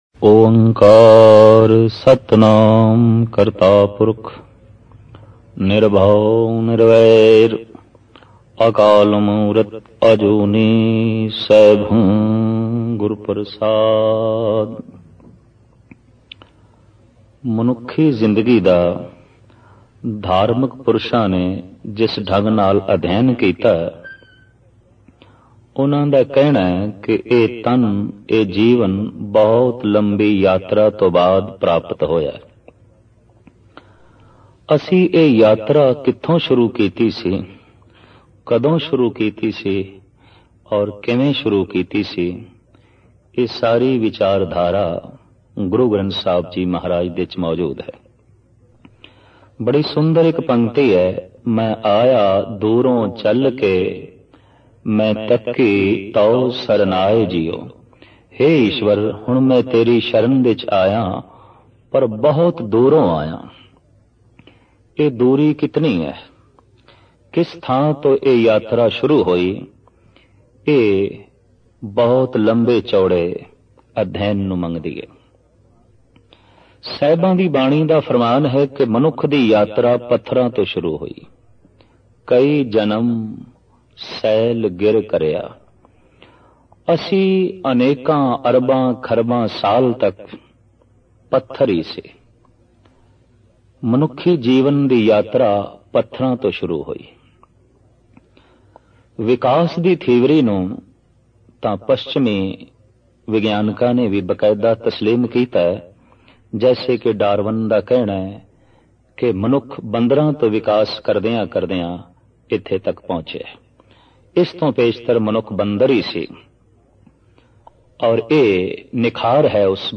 Album: Mai Aya Dooron Chal Ke Genre: Gurmat Vichar